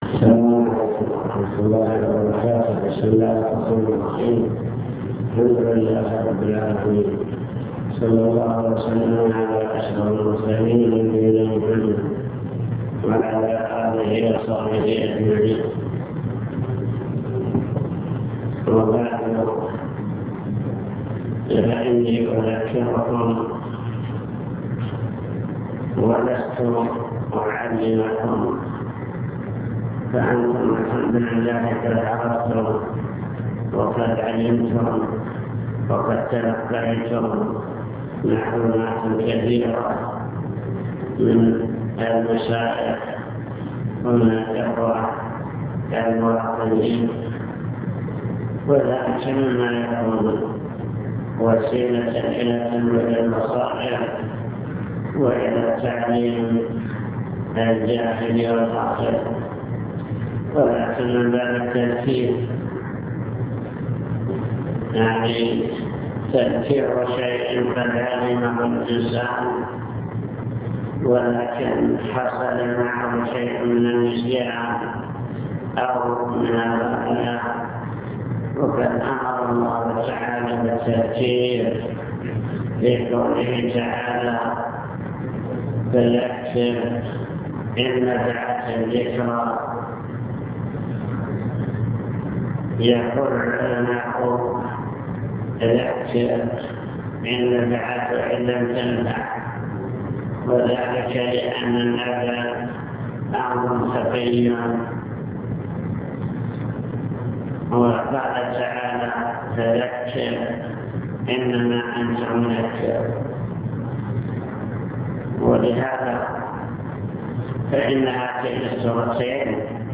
المكتبة الصوتية  تسجيلات - محاضرات ودروس  محاضرة بعنوان توجيهات للأسرة المسلمة طرق وأسباب صلاح الأبناء